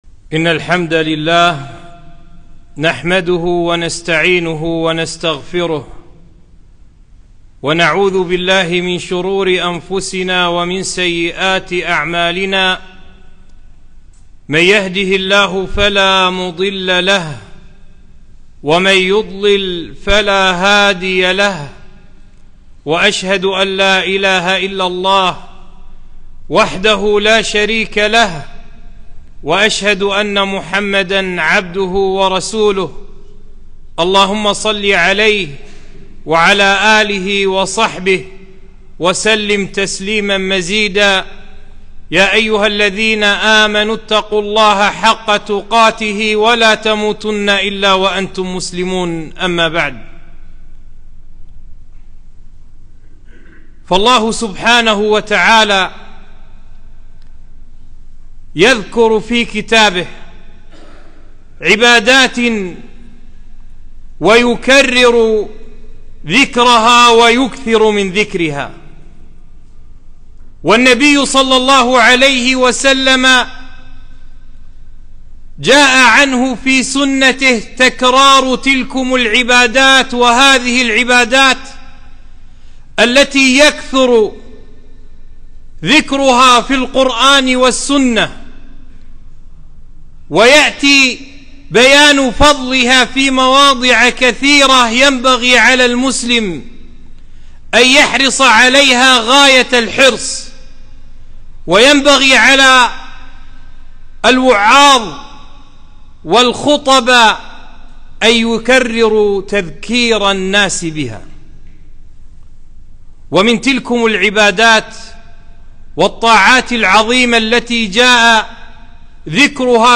خطبة - فضل الصبر